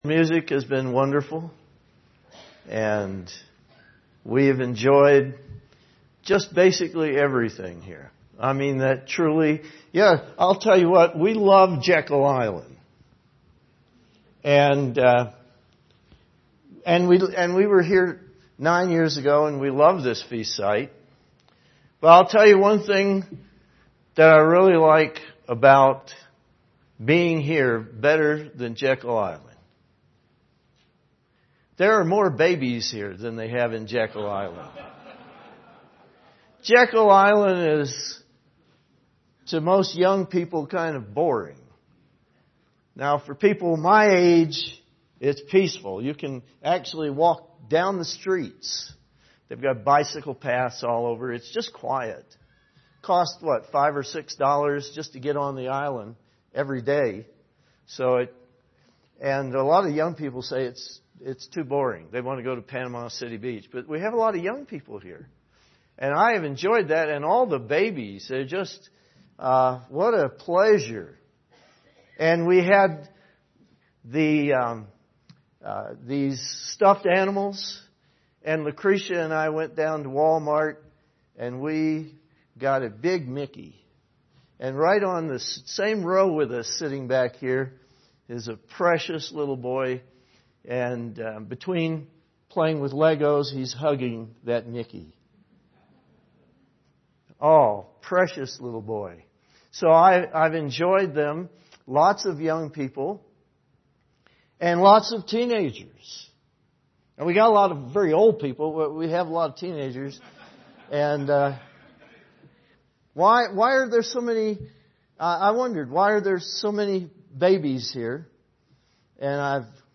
This sermon is directed to the teenagers who have grown up in the church. It shows that God has deliberately allowed Satan to blind the whole world but that God intends to resurrect all and to give them a first chance at salvation.
This sermon was given at the Steamboat Springs, Colorado 2015 Feast site.